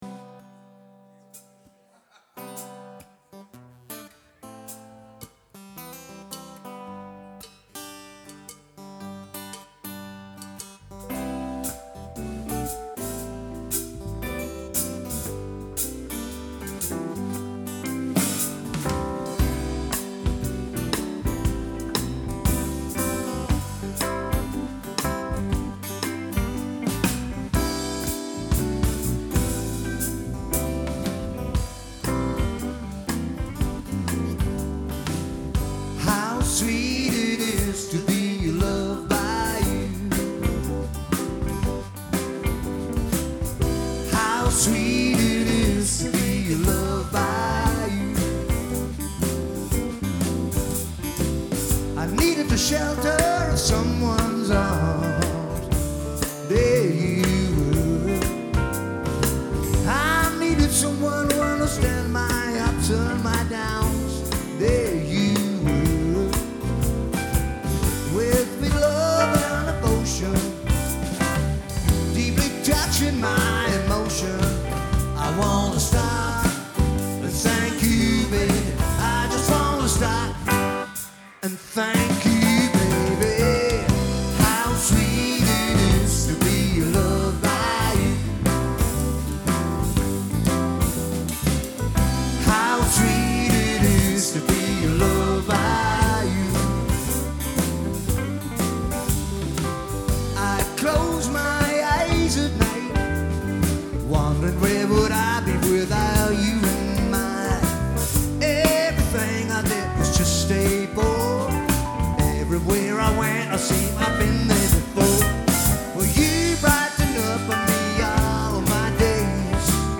Anfang Juli hab ich bei einem kleineren Gig (ca. 250 Besucher) ein paar Kumpels betreut, und den kompletten Gig auf USB Stick mitgeschnitten. Anbei ein kleines Beispiel wie schnell man in guter Qualität direkt mit dem Ui24R (ohne Zuhilfenahme einer DAW) einen Mix über Kopfhörer erstellen kann, welcher vollkommen ausreichend für Analysezwecke ist.